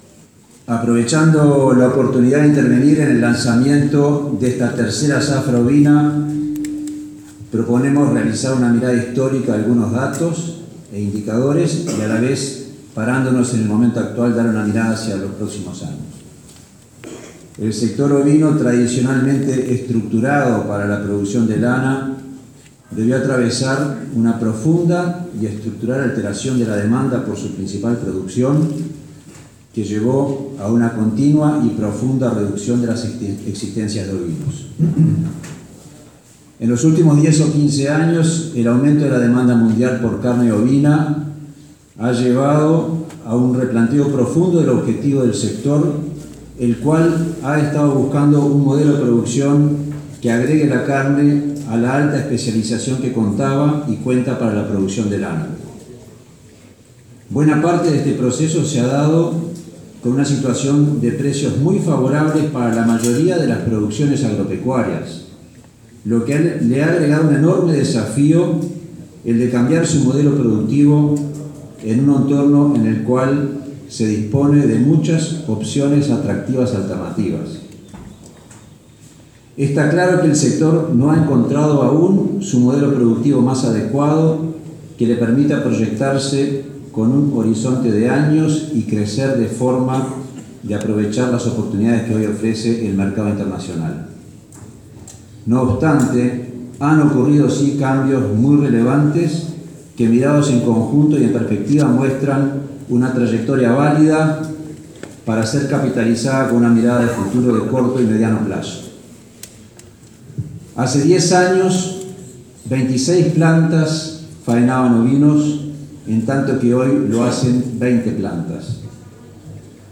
Se inició la zafra ovina en San Carlos con participación de las Instituciones vinculadas al PENRO, Plan Estratégico Nacional del Rubro Ovino (SUL, INAC, MGAP, entre otros).
El Presidente de INAC Federico Stanham, incluyó en su discurso una visión histórica del rubro, destacando que en los últimos diez años, el precio de la carne ovina en los mercados internacionales ha aumentado 150 %, si bien parecería que este incremento se ha moderado. Agregó que la demanda crece más que la oferta y que es necesario profundizar los accesos sanitarios y arancelarios para el producto.